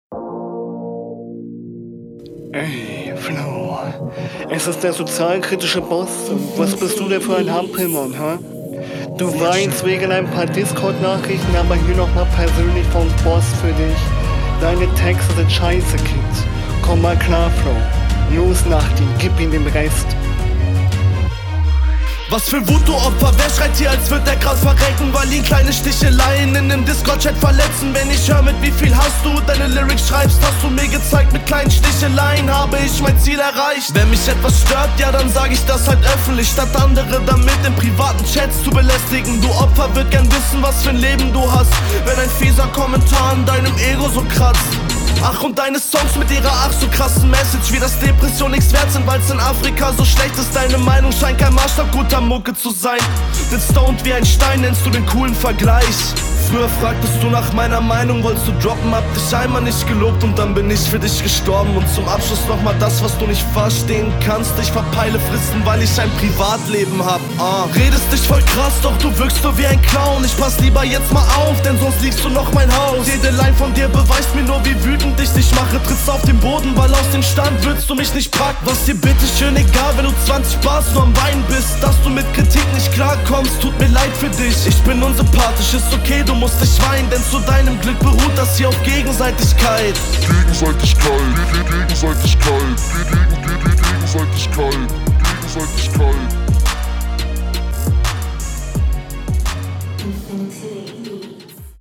flowlich bist du mir an paar stellen zu wackelig, gerade am anfang, legt sich gegen …